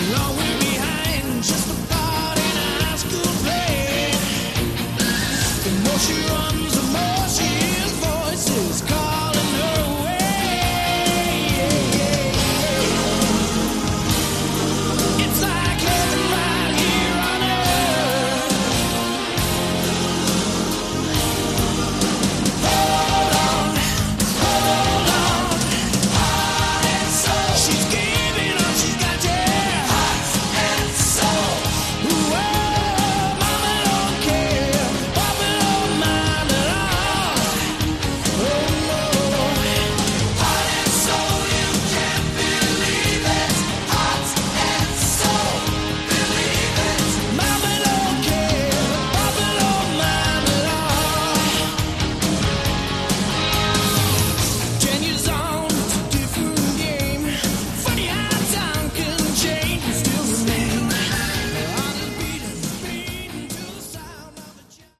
Category: AOR
lead vocals
keyboards, vocals
guitars, vocals
bass
drums